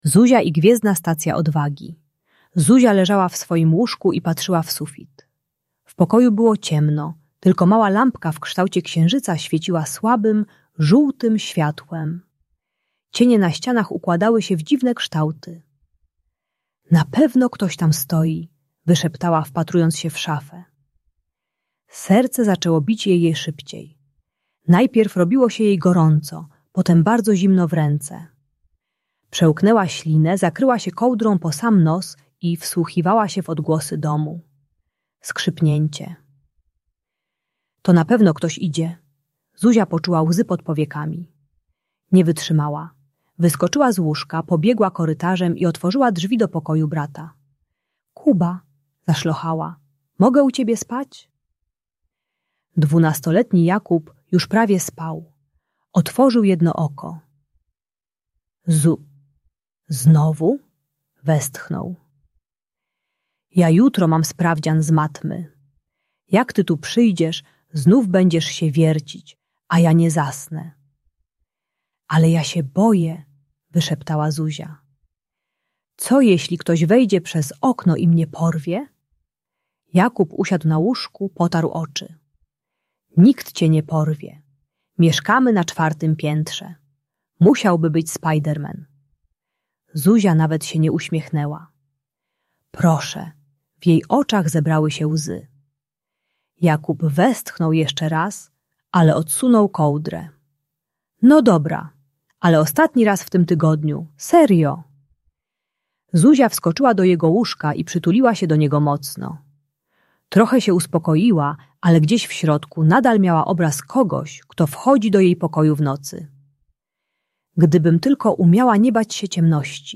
Zuzia i Gwiezdna Stacja Odwagi - Lęk wycofanie | Audiobajka